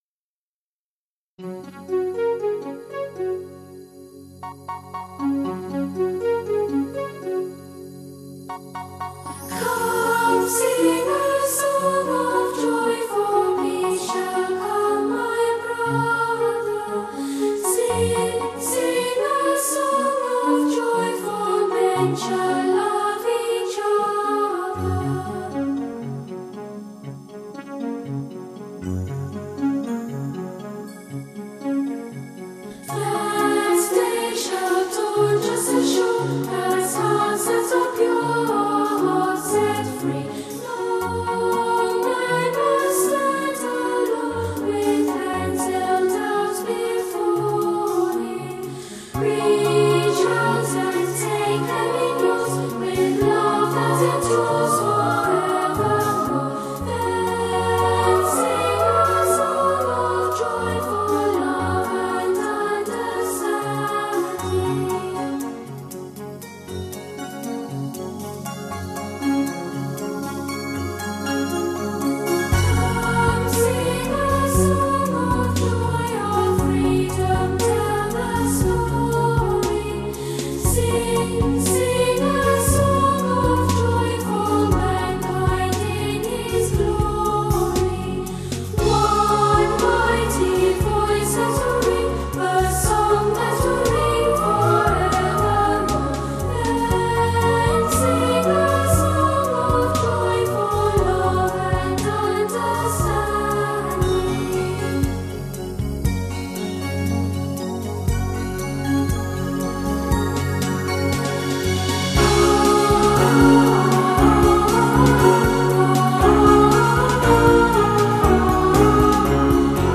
音乐类型：童声合唱